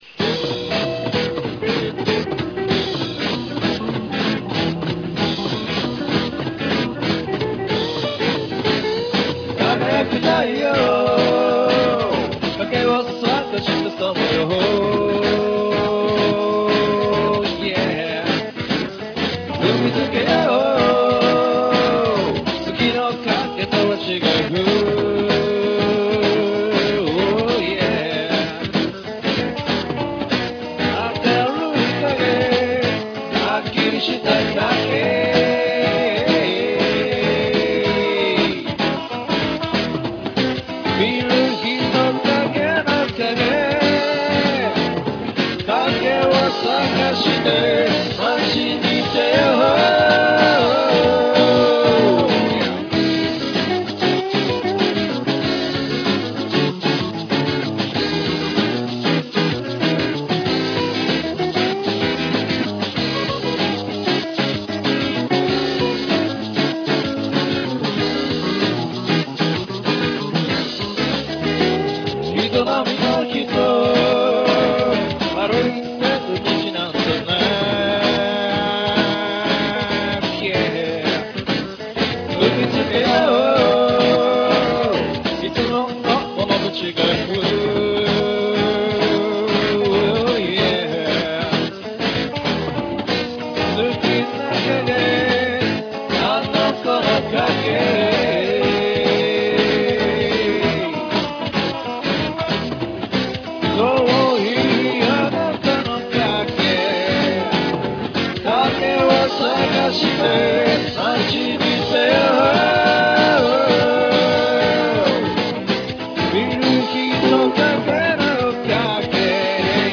この曲はロカ＋ジャズを狙って作った曲です。